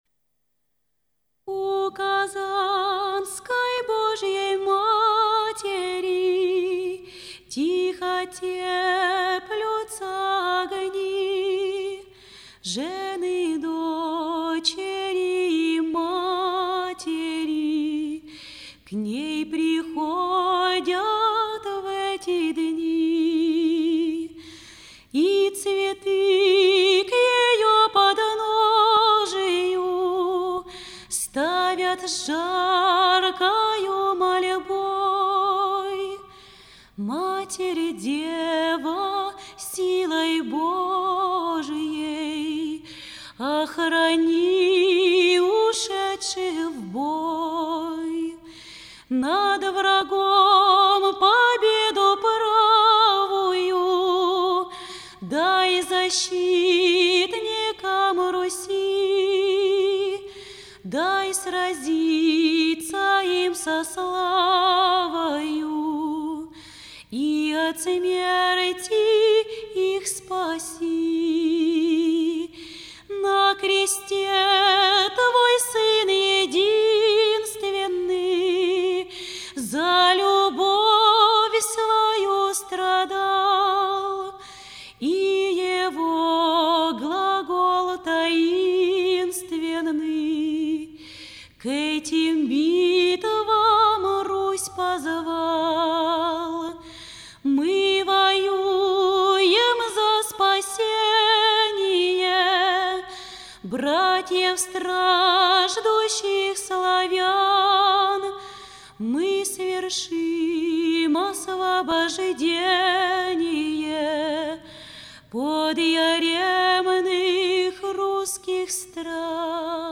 Музыкальные же номера стали прекрасным дополнением встречи — зрители услышали песню «Покров Богородицы» и молитву перед иконой Казанской Божией Матери, у которой ополченцы и русский народ стояли на коленях три дня, прося помощи и заступления перед освобождением Москвы от польских иноземцев.